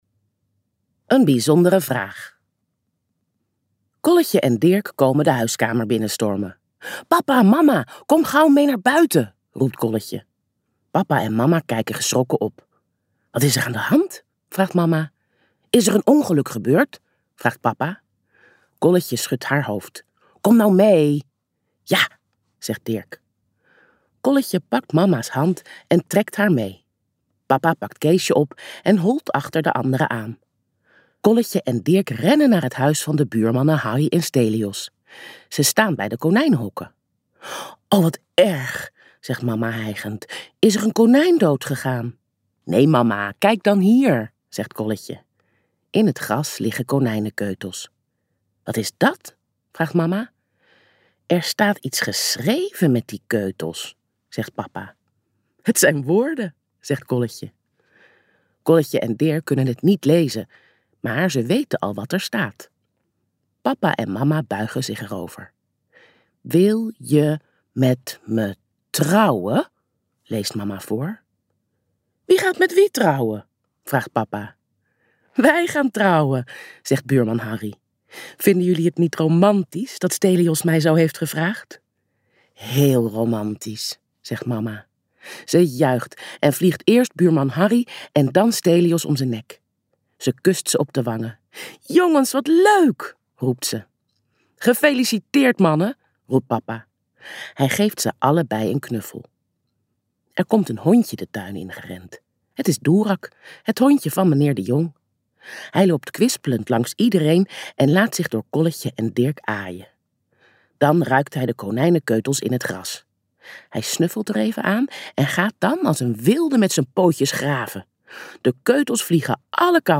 Vier feest met Kolletje en Dirk in dit superleuke luisterboek.